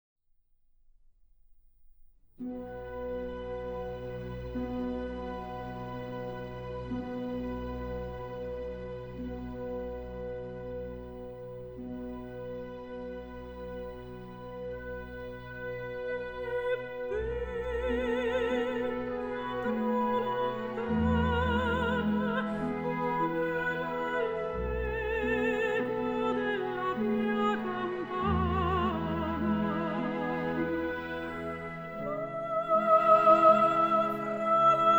Opera Classical
Жанр: Классика